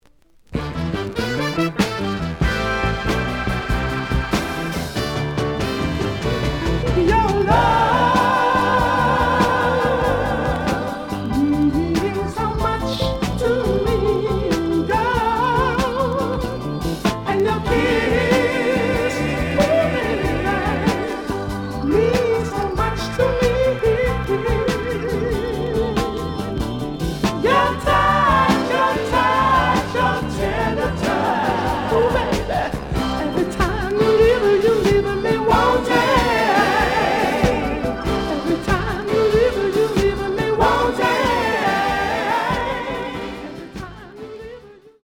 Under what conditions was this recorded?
The audio sample is recorded from the actual item. Writing on A side label. A side plays good.)